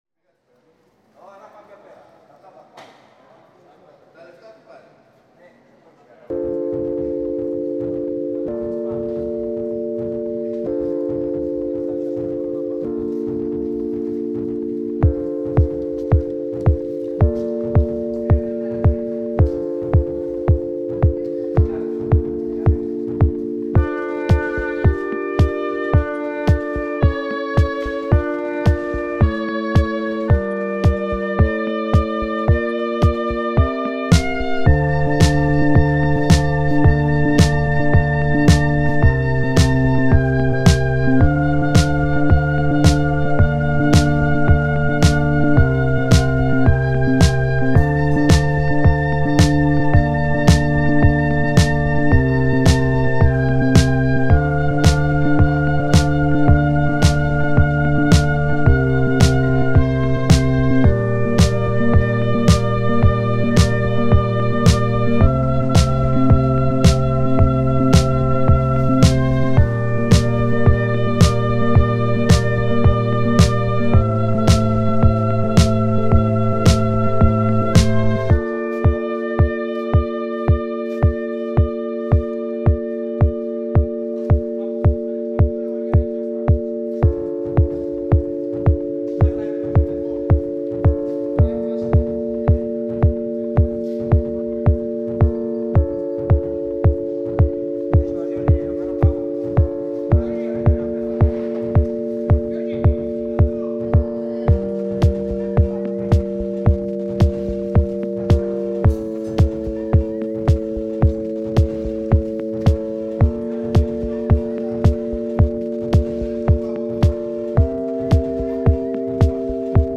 a boy / girl duet experimenting with samples and stuff